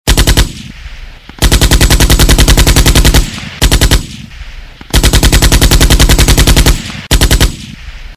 2. Стрельба из пулемёта